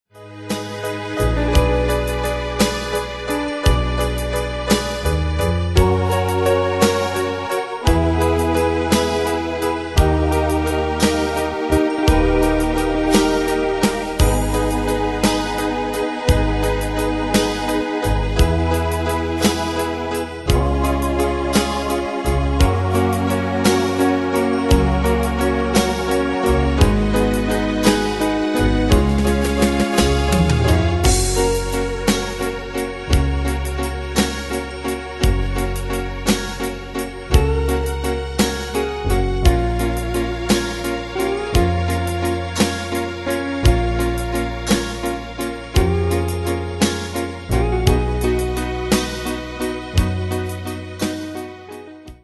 Danse/Dance: Ballade Cat Id.
Pro Backing Tracks